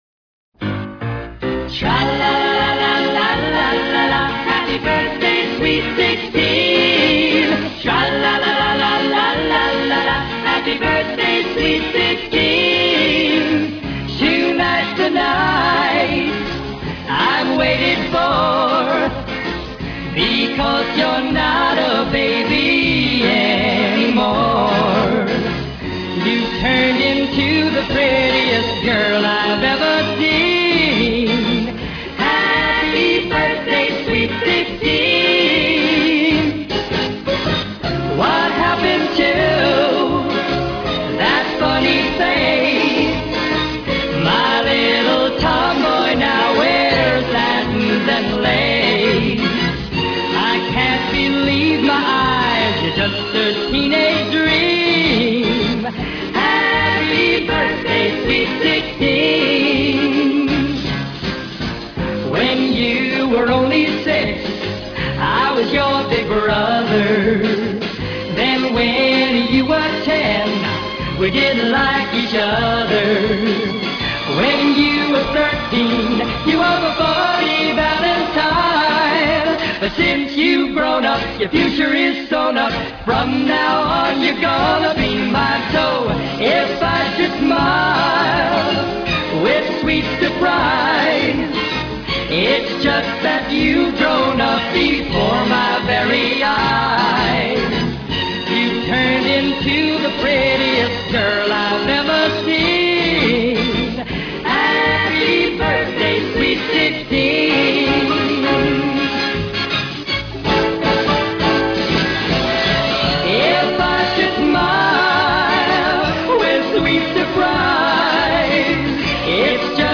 THESE SOUNDS ARE IN REALAUDIO STEREO!